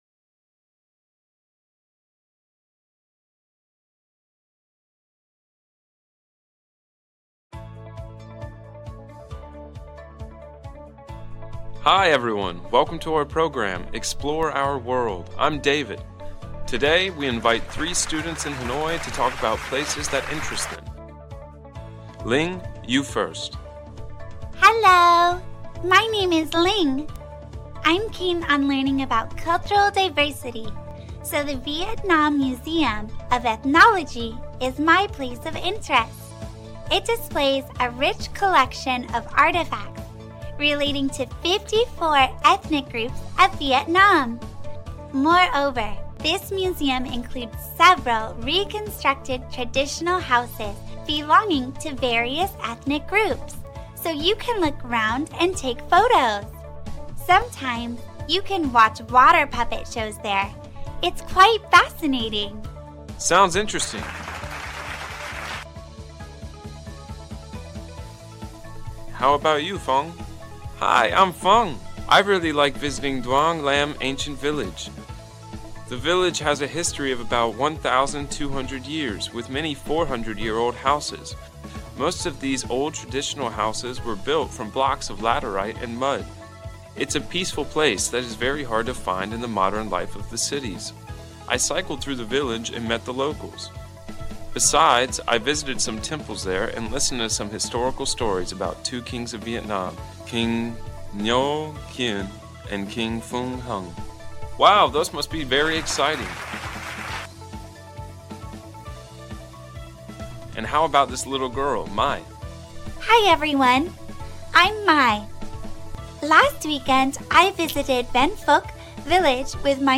Trong bài viết này, bạn sẽ được thực hành nghe qua các đoạn hội thoại, bài nói và bài tường thuật đa dạng, kèm theo bài tập trắc nghiệm – điền từ – nối thông tin giúp củng cố kiến thức một cách hiệu quả và thú vị.